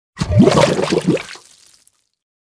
Звуки бульканья